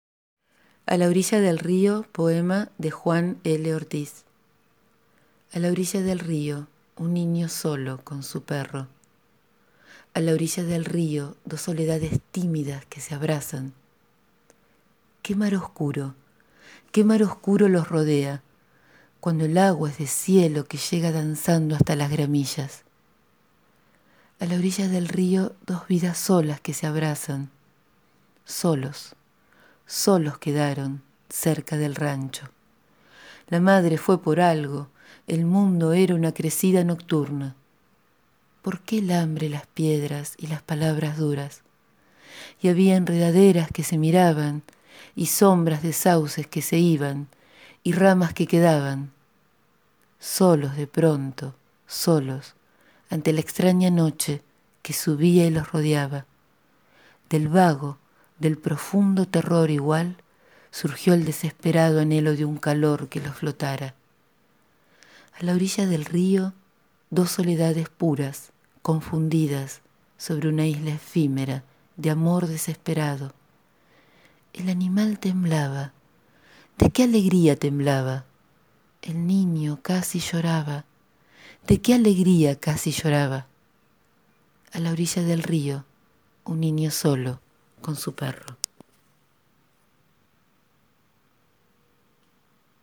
Leer en voz alta un poema, es también hacerse poema, es contarse y cantarse